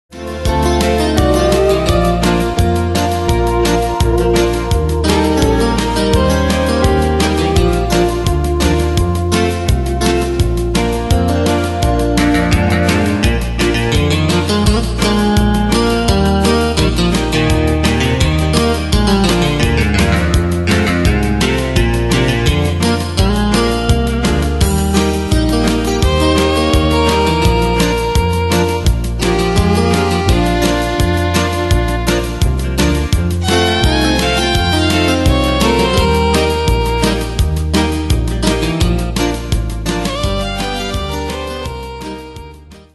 Style: Country Année/Year: 1998 Tempo: 169 Durée/Time: 2.33
Danse/Dance: Country Cat Id.
Pro Backing Tracks